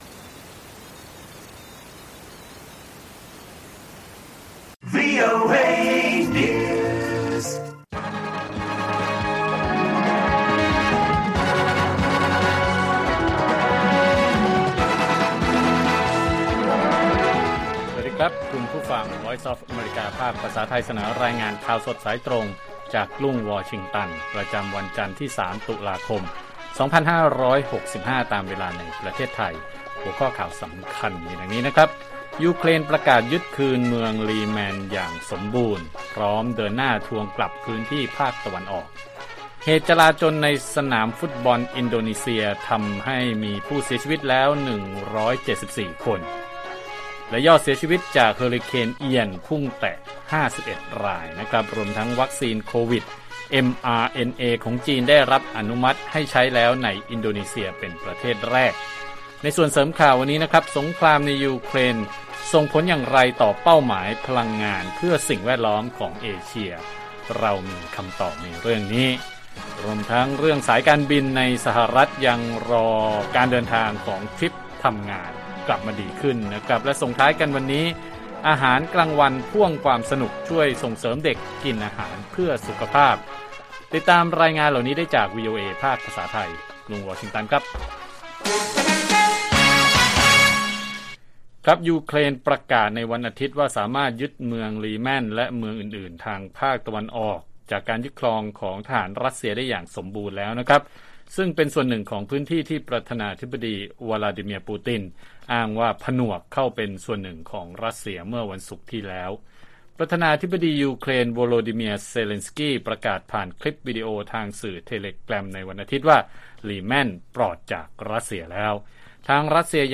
ข่าวสดสายตรงจากวีโอเอไทย จันทร์ ที่ 3 ต.ค. 65